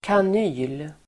Uttal: [kan'y:l]